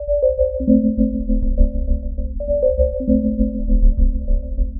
tx_synth_100_waft_CPenta.wav